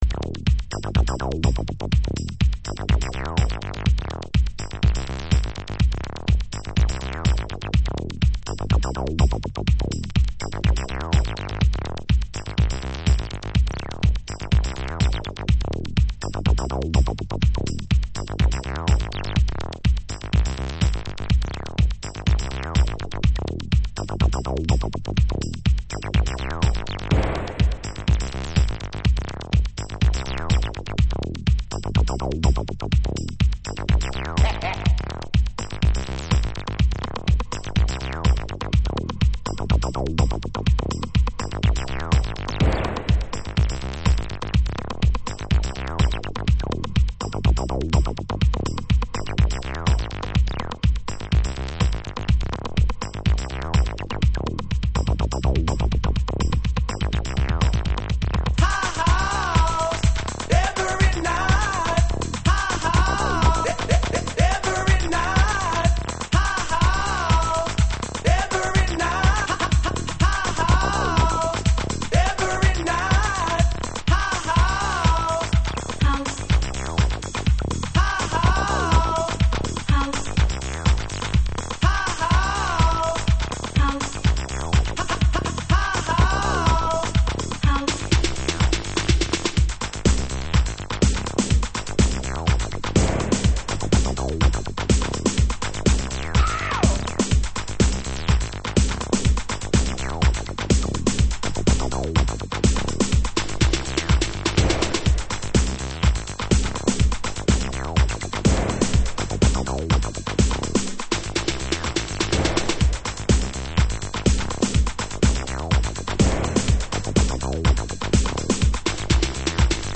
ストレートなサンプリングとTRの音を弄れるブレイクビーツハウス。